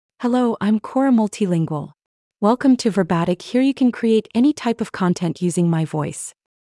FemaleEnglish (United States)
Cora MultilingualFemale English AI voice
Cora Multilingual is a female AI voice for English (United States).
Voice sample
Listen to Cora Multilingual's female English voice.